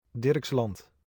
Dirksland (Dutch pronunciation: [ˈdɪr(ə)kslɑnt]